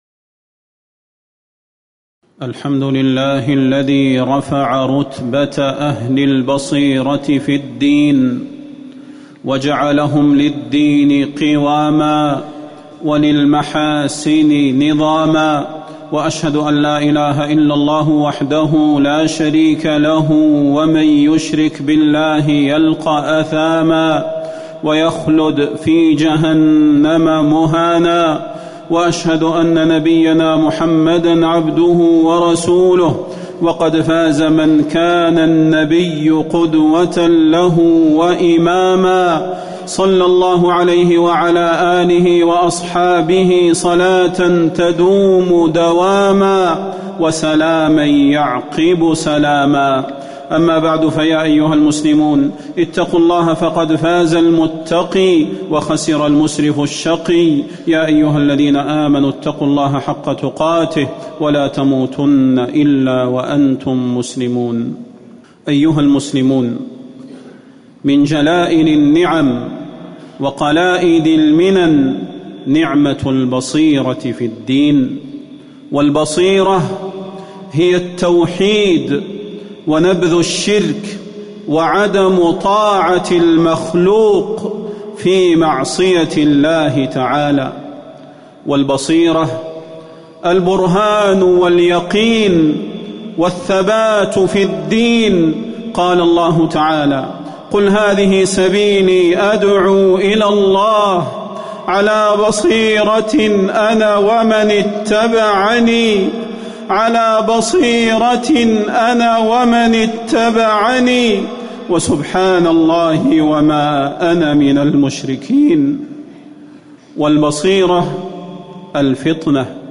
تاريخ النشر ٢٦ جمادى الأولى ١٤٤٠ هـ المكان: المسجد النبوي الشيخ: فضيلة الشيخ د. صلاح بن محمد البدير فضيلة الشيخ د. صلاح بن محمد البدير البصيرة في الدين